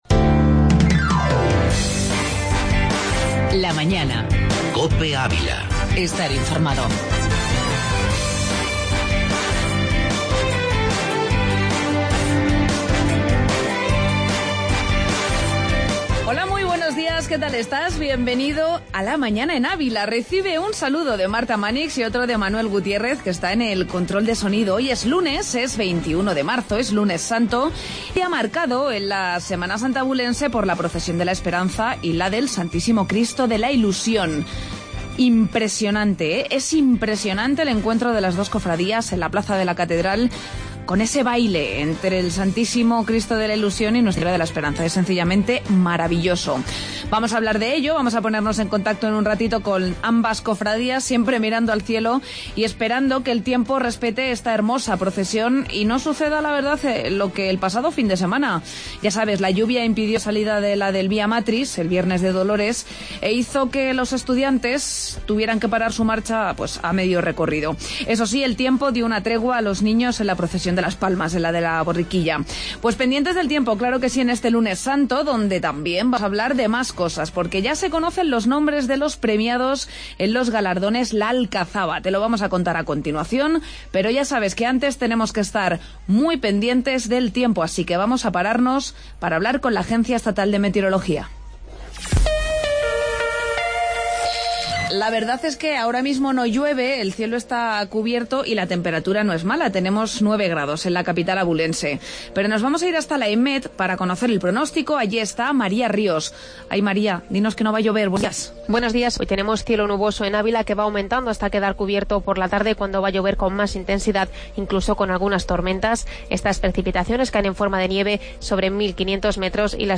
AUDIO: Entrevista premios alcazaba y Espacio ESSE